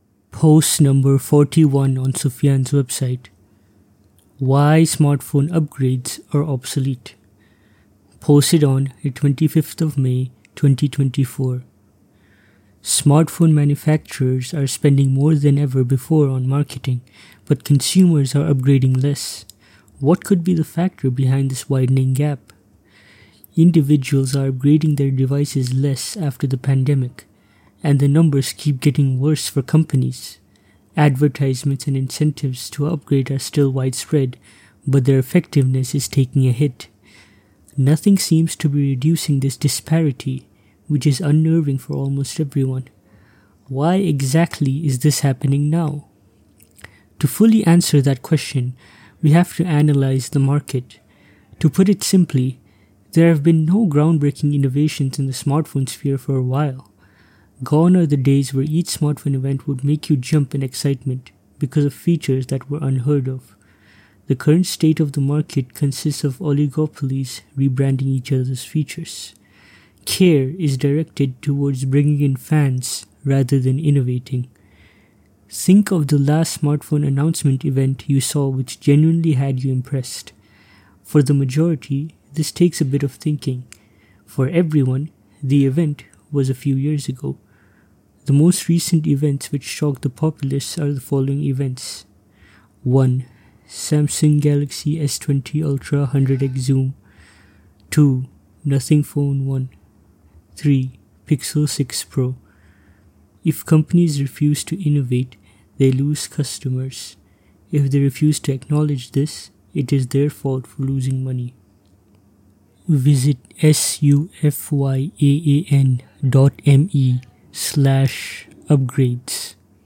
voiceover.mp3